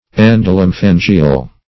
endolymphangial - definition of endolymphangial - synonyms, pronunciation, spelling from Free Dictionary
endolymphangial.mp3